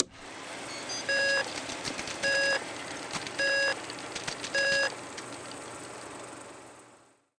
Menu Antique Projector Countdown Sound Effect
Download a high-quality menu antique projector countdown sound effect.
menu-antique-projector-countdown.mp3